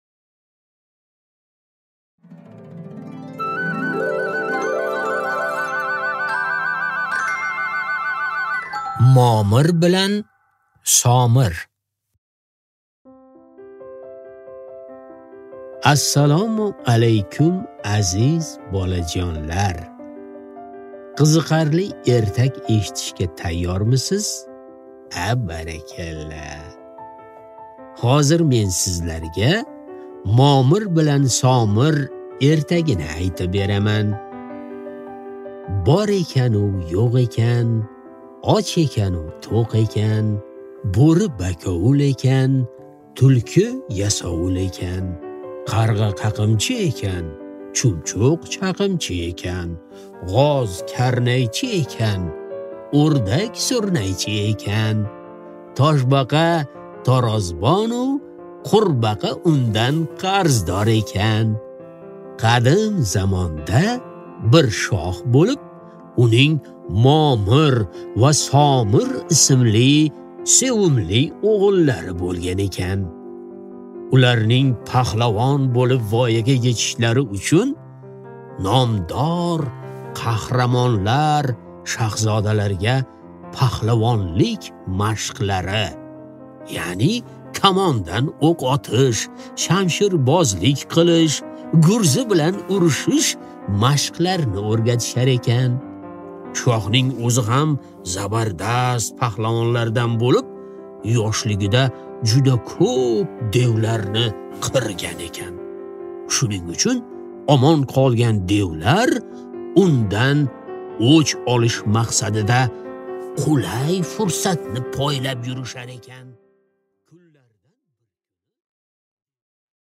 Аудиокнига Momir bilan Somir